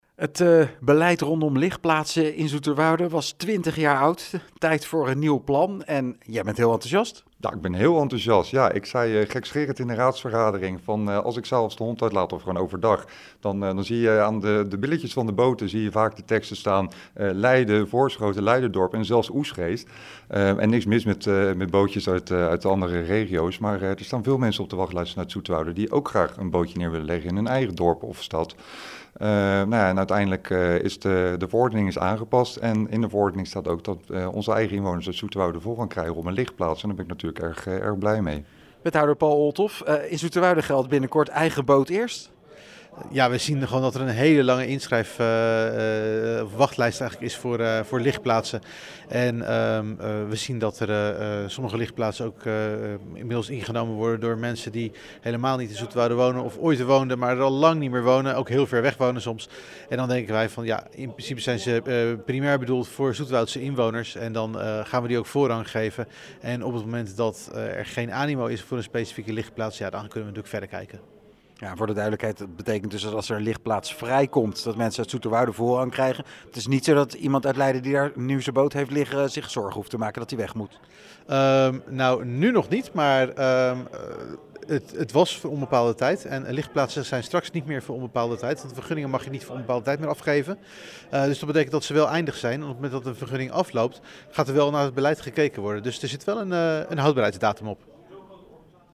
PZ-raadslid Mike van Es en wethouder Paul Olthof over de nieuwe ligplaatsverordening in Zoeterwoude: